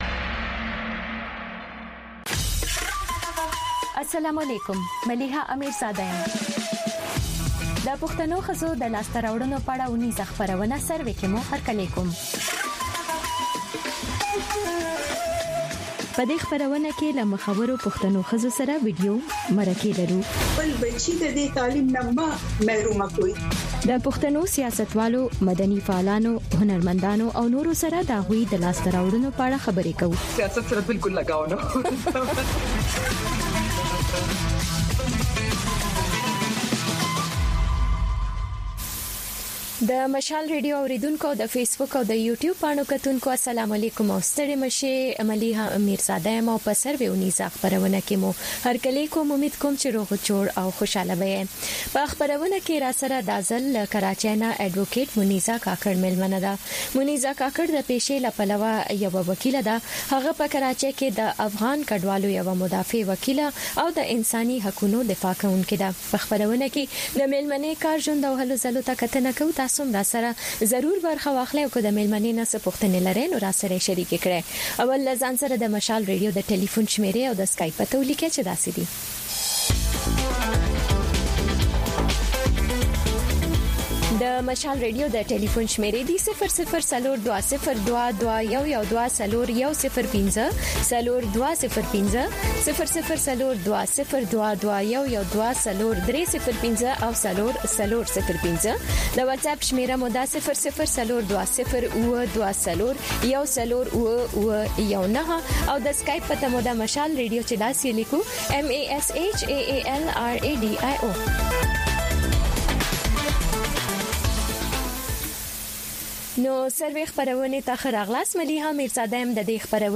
خبرونه
د وی او اې ډيوه راډيو سهرنې خبرونه چالان کړئ اؤ د ورځې دمهمو تازه خبرونو سرليکونه واورئ.